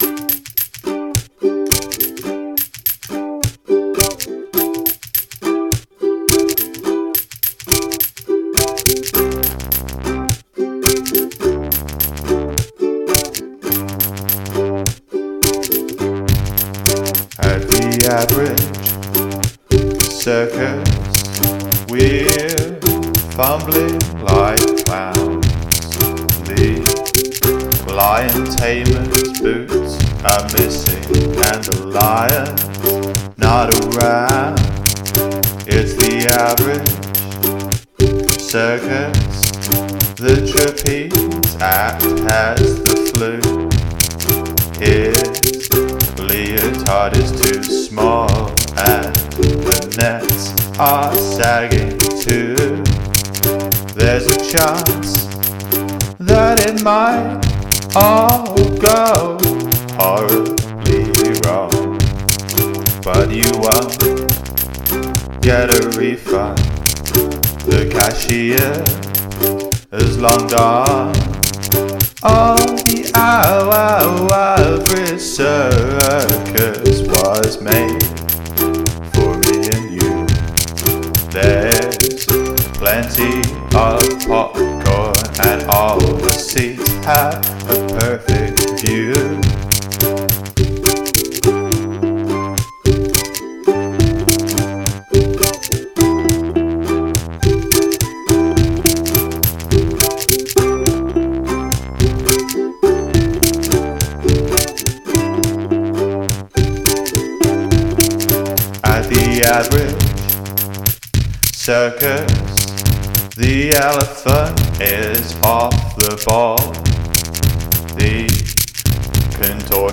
Use something from your garbage bin as an instrument